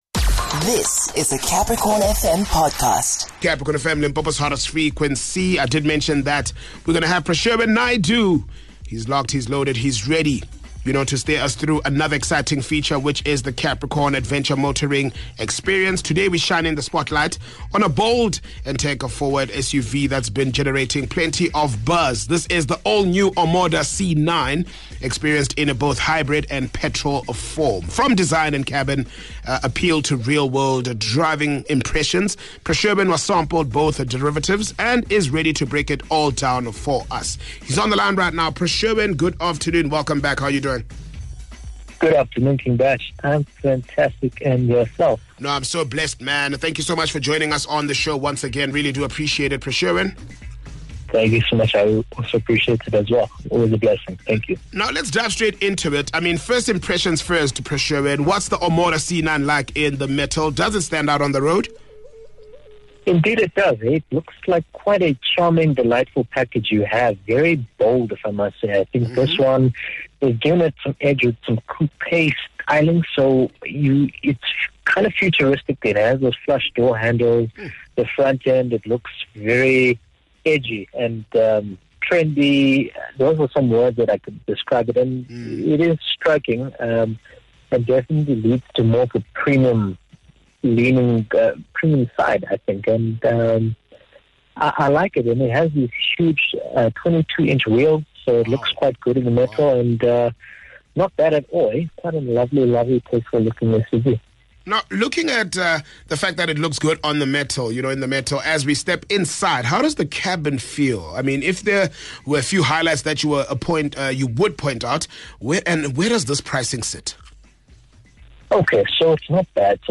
He touches on the C9’s performance in hybrid and petrol guise. The conversation wraps up with the motoring tip of the week.